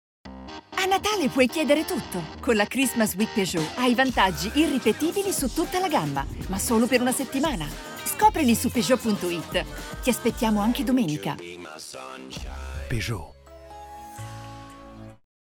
Profi-Sprecherin Italienisch Muttersprache
Sprechprobe: eLearning (Muttersprache):
female voice over talent italian mother tongue